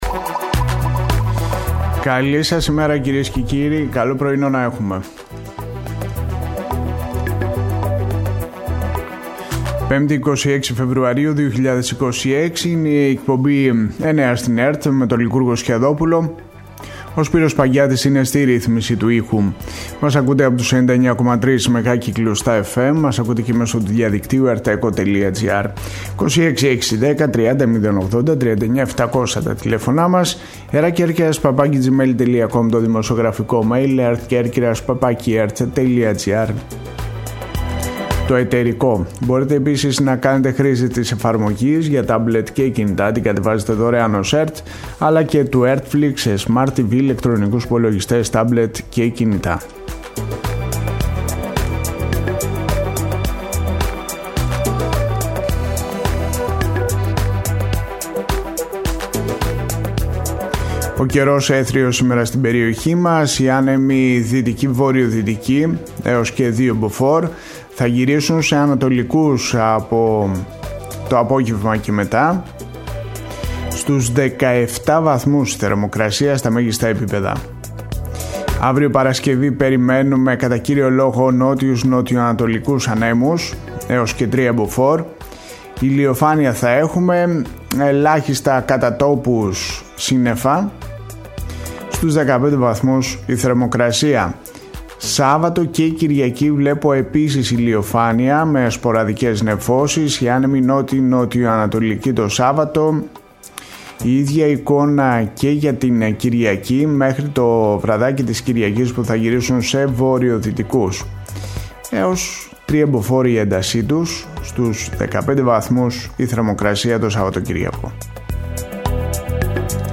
Στη σημερινή εκπομπή, ακούγονται ηχογραφημένα αποσπάσματα από την κινητοποίηση για την άμεση αποκατάσταση των δρόμων που παρουσιάζουν φθορές από τις συνεχόμενες νεροποντές.